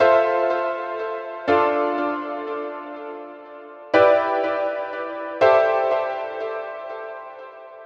Rnb Piano
标签： 122 bpm RnB Loops Piano Loops 1.32 MB wav Key : Unknown